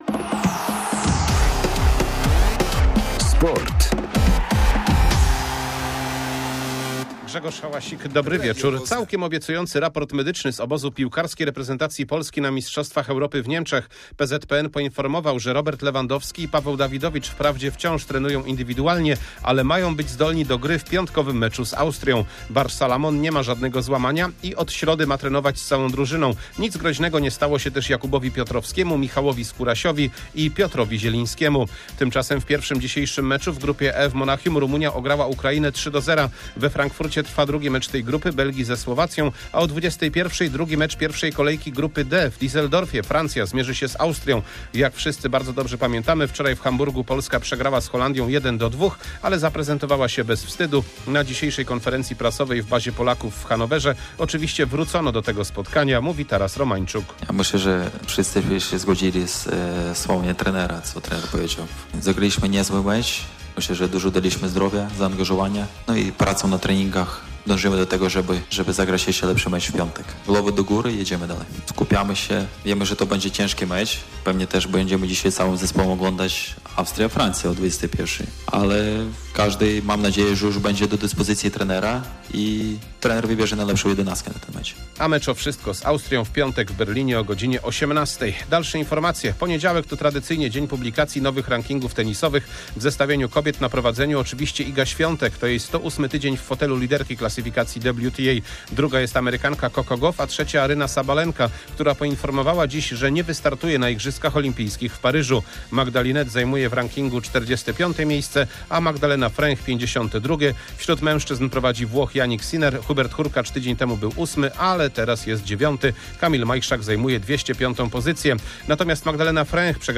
17.06.2024 SERWIS SPORTOWY GODZ. 19:05